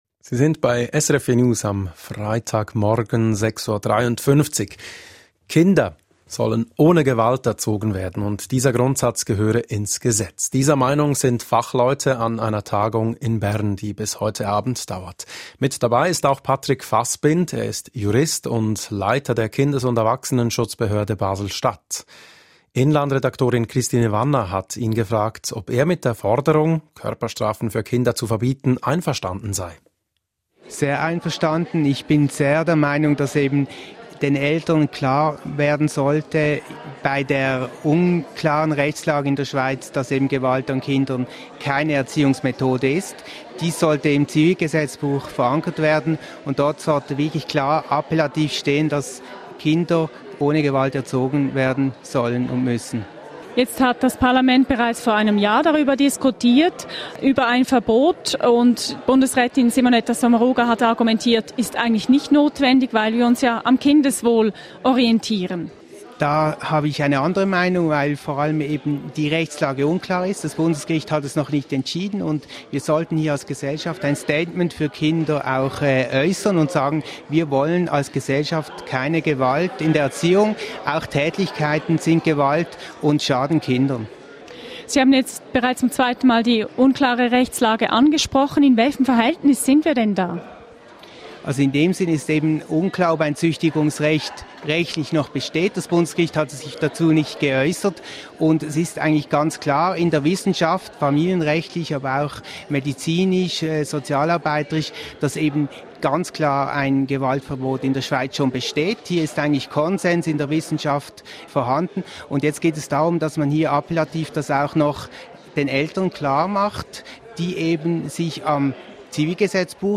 Interview zum Thema Gewaltverbot in der Erziehung, Radio SRF 4 News, 4. Mai 2018